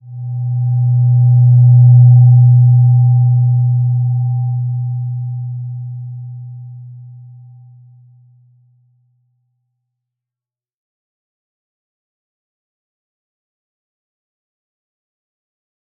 Slow-Distant-Chime-B2-mf.wav